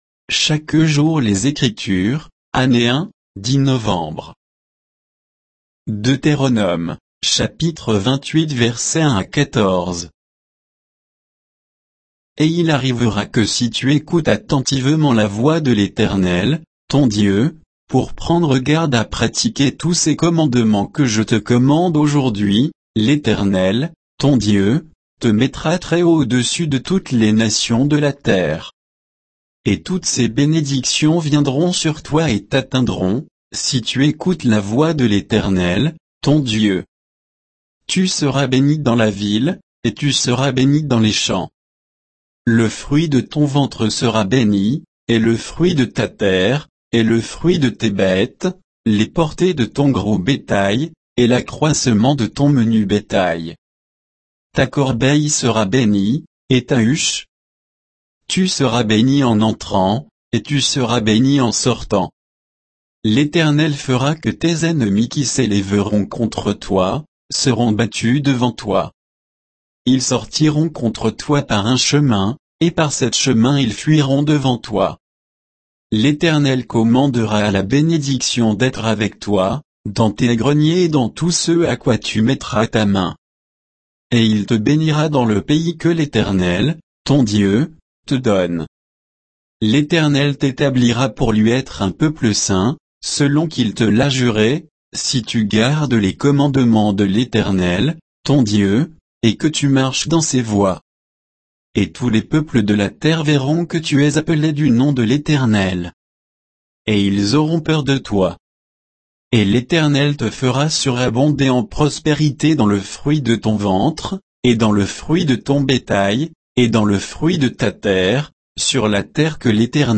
Méditation quoditienne de Chaque jour les Écritures sur Deutéronome 28